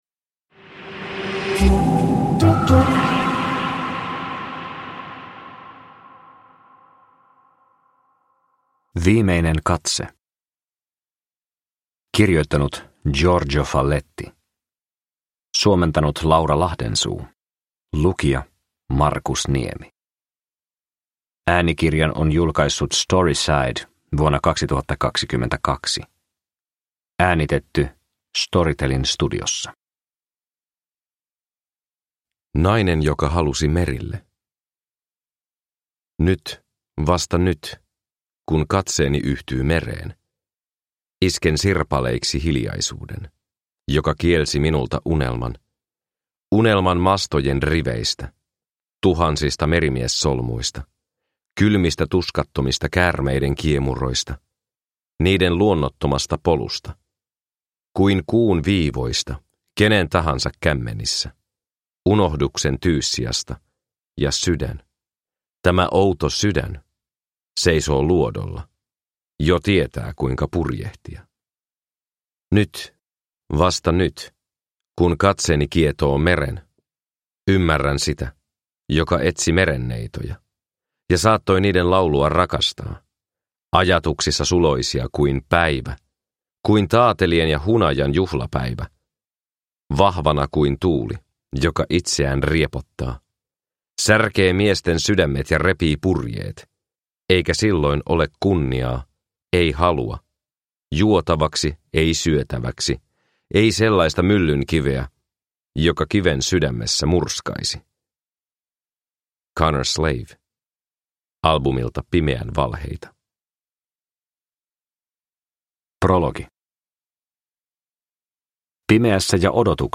Viimeinen katse – Ljudbok – Laddas ner